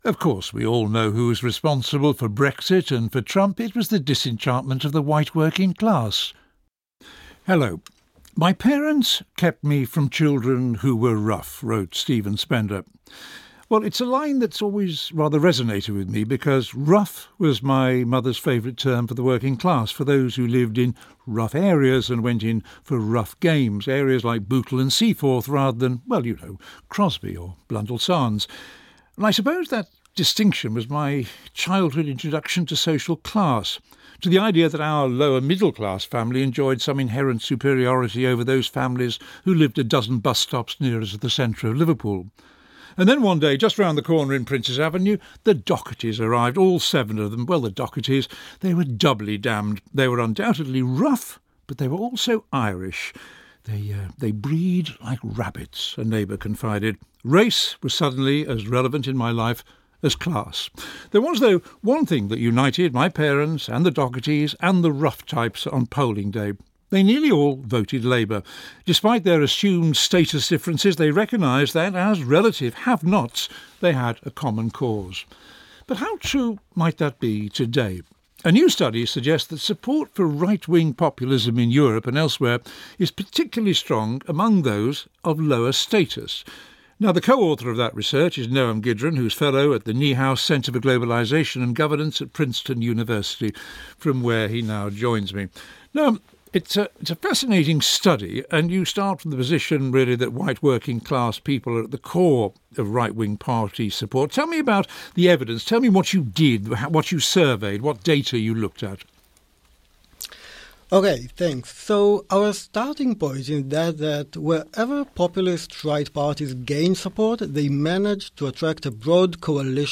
This is a discussion on BBC Radio 4’s Thinking Allowed programme on ‘the white working class’